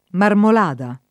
Marmolada [ marmol # da ] top. f.